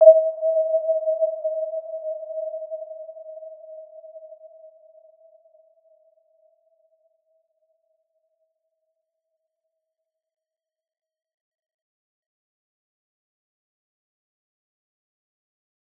Warm-Bounce-E5-mf.wav